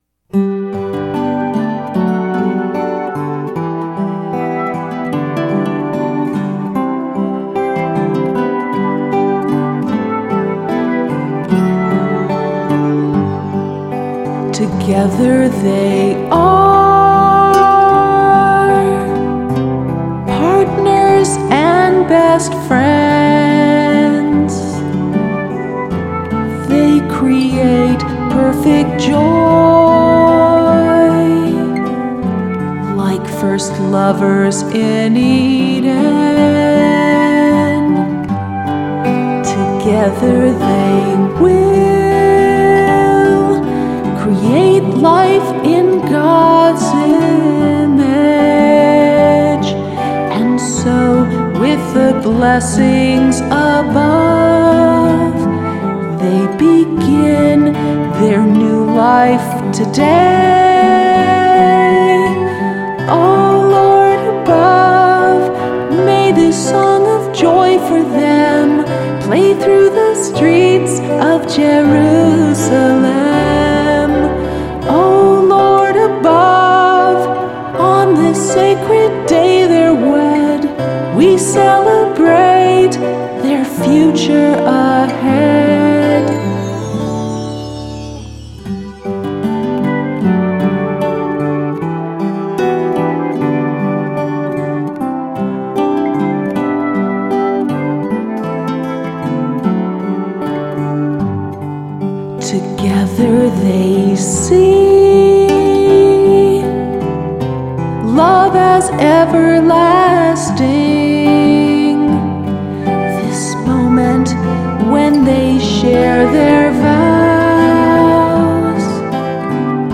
Totally embarrassing, but this is my version from 2010 when I first began singing again.